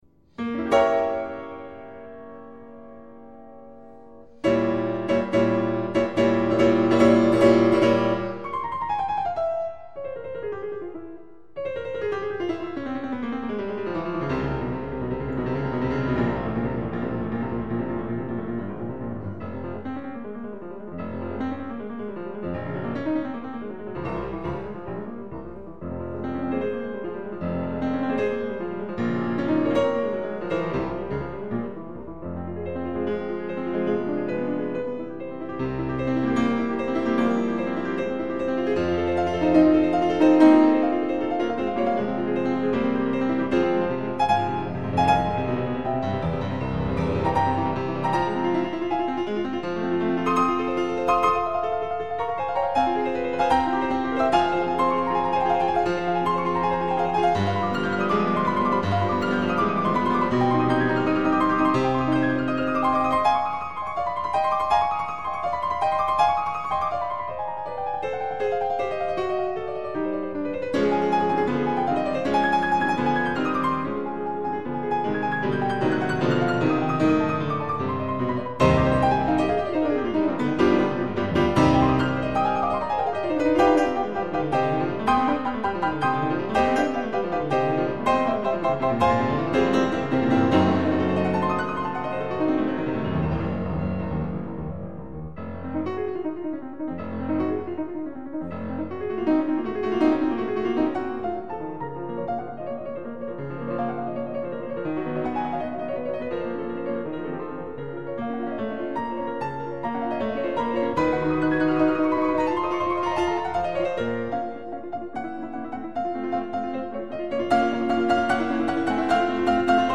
Л. Бетховен. Соната № 23 «Аппассионата». Часть 3